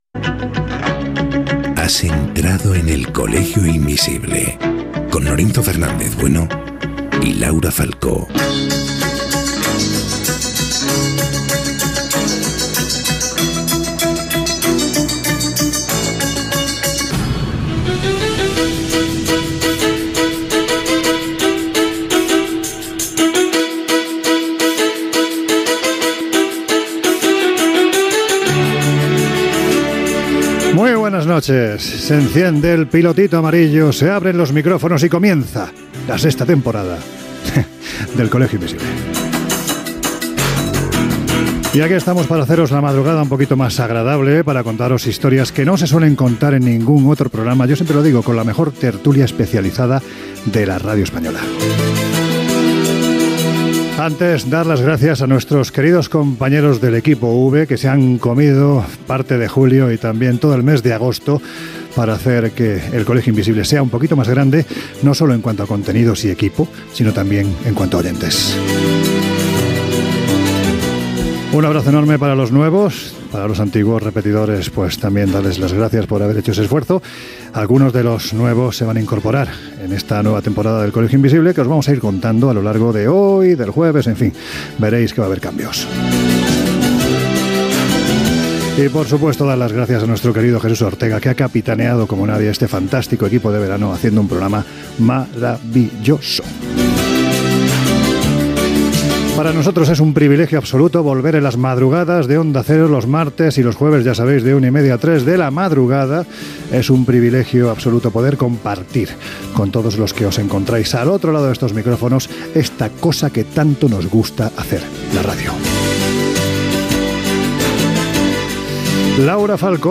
Careta, presentació de la sisena temporada del programa de misteris, història i llegendes.
Entreteniment